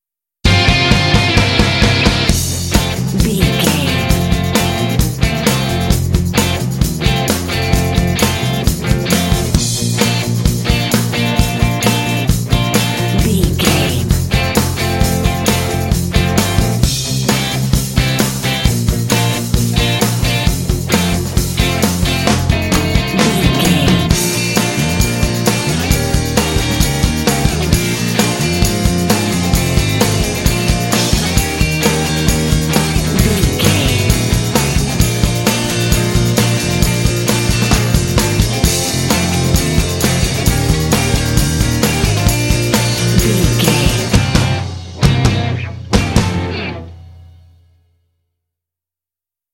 Uplifting
Ionian/Major
bouncy
happy
electric guitar
drums
bass guitar
saxophone